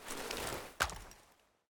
Spas-12 Reanimation / gamedata / sounds / weapons / spas / open.ogg.bak